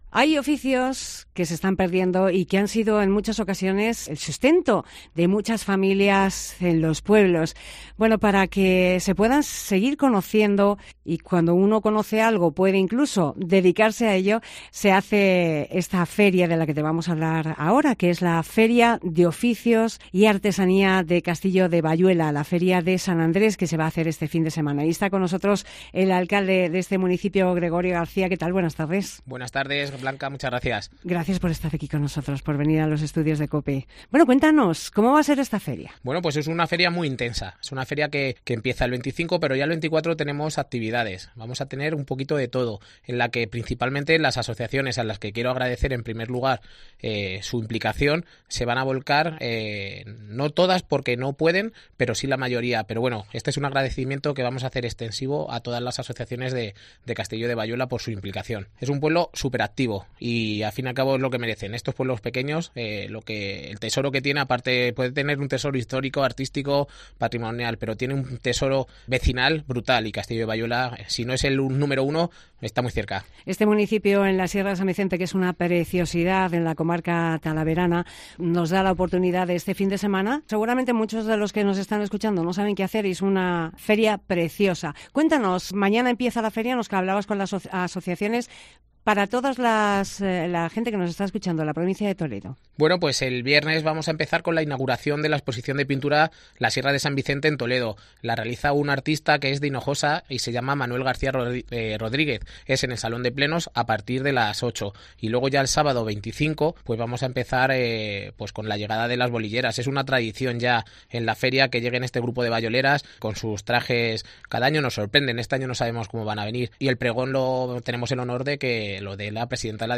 El alcalde, Gregorio García, nos cuenta todos los detalles